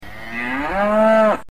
Die Farmerama Tierstimmen
Kuh
Kuh.wav.mp3